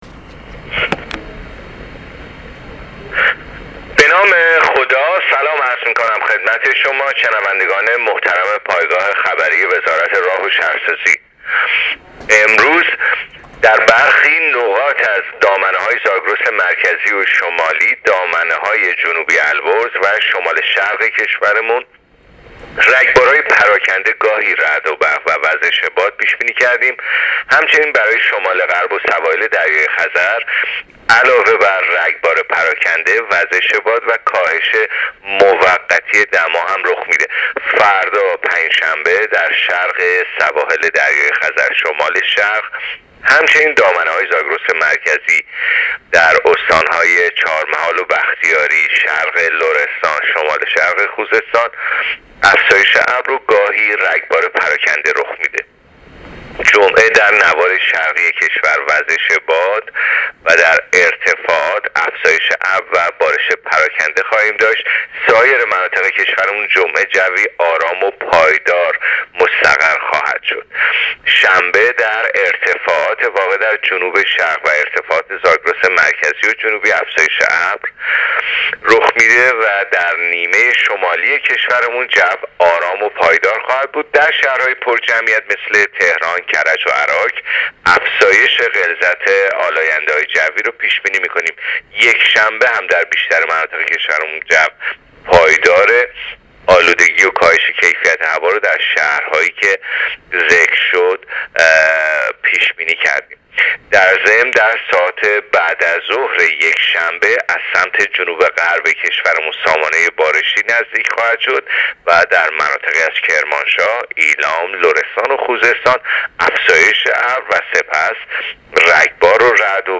گزارش رادیو اینترنتی پایگاه‌ خبری از آخرین وضعیت آب‌وهوای ۳۰ آبان؛